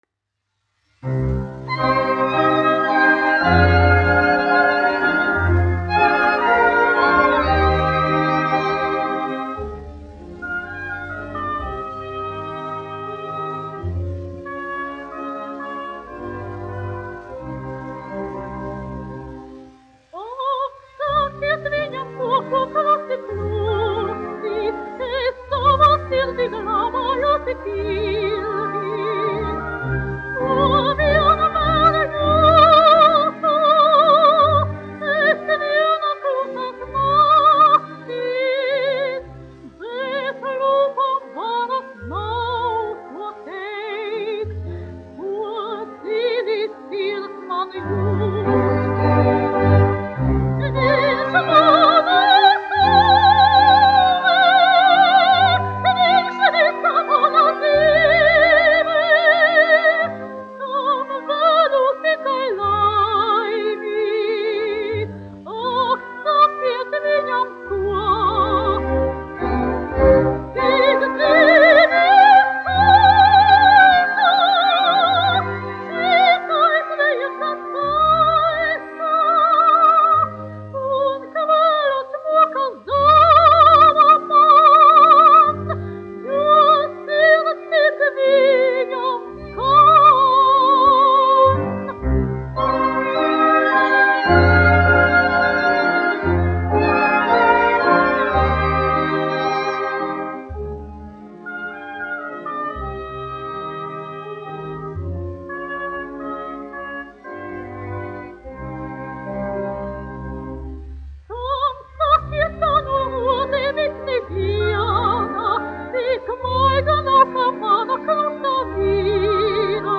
1 skpl. : analogs, 78 apgr/min, mono ; 25 cm
Populārā mūzika -- Itālija
Dziesmas (vidēja balss)
Skaņuplate